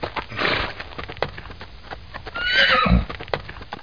1 channel
horses1.mp3